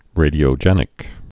(rādē-ō-jĕnĭk)